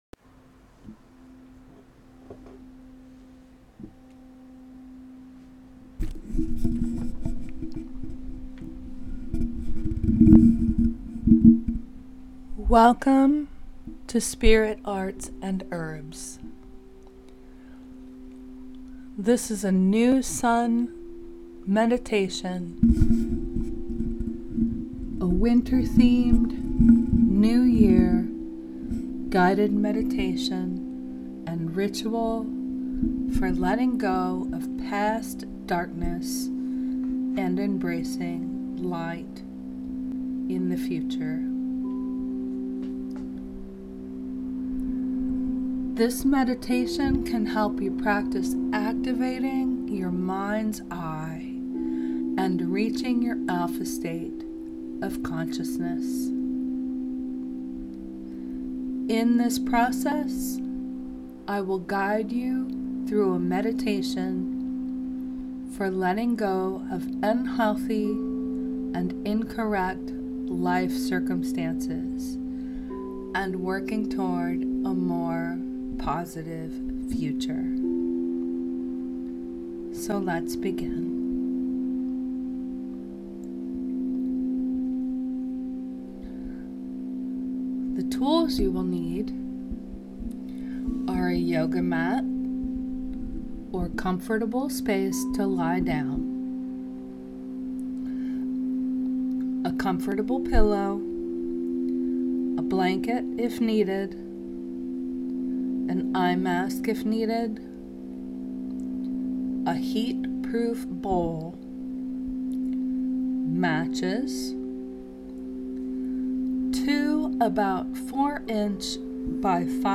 MEDITATION & RITUAL - New Sun, New Year, New Beginnings